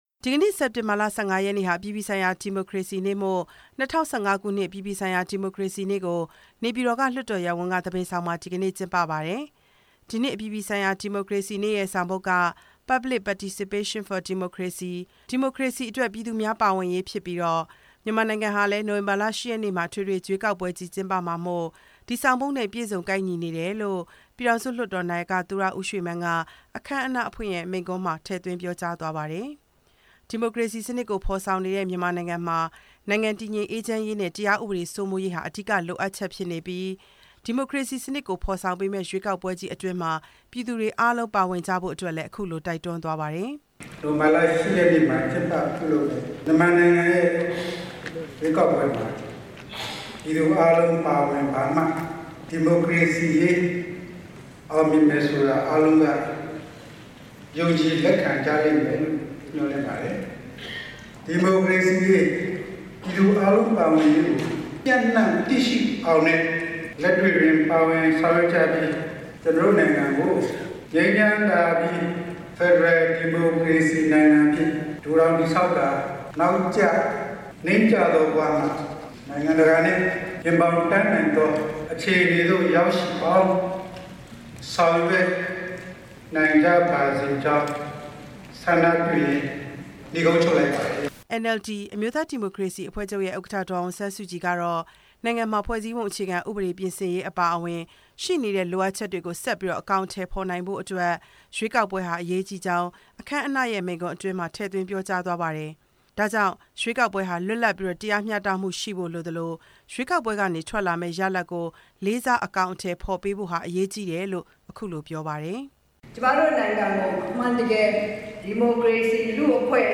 ၂ဝ၁၅ ခုနှစ် အပြည်ပြည်ဆိုင်ရာ ဒီမိုကရေစီနေ့ အခမ်းအနားကို နေပြည်တော် လွှတ်တော်ရပ်ဝန်းက သဘင်ဆောင်မှာ ကျင်းပခဲ့ပါတယ်။